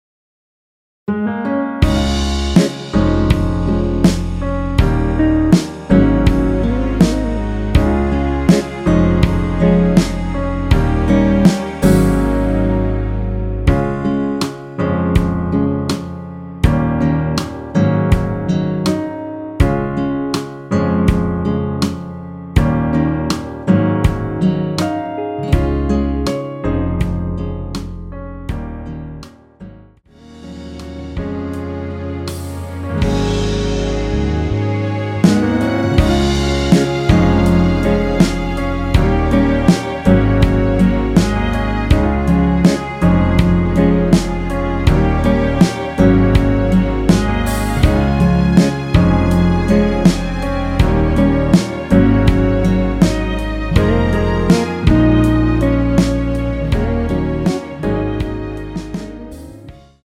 원키에서(-1)내린 MR입니다.
Db
앞부분30초, 뒷부분30초씩 편집해서 올려 드리고 있습니다.